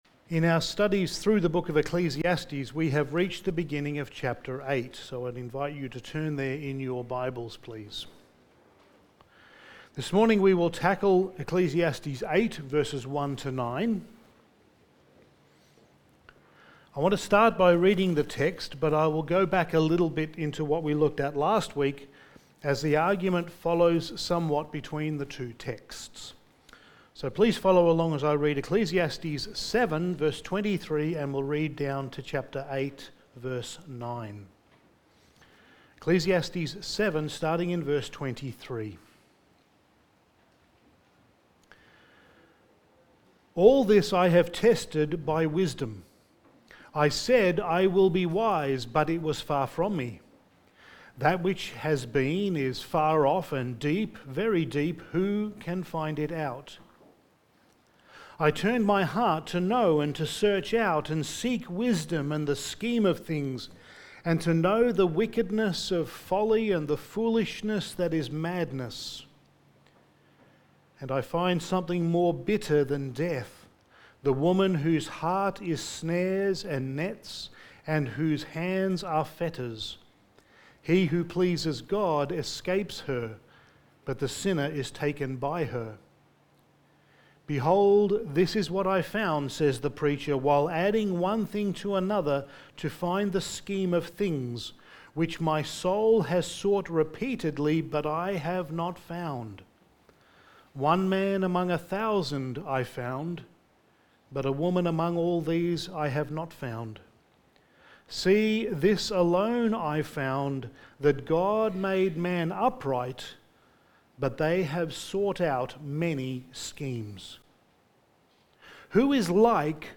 Passage: Ecclesiastes 8:1-9 Service Type: Sunday Morning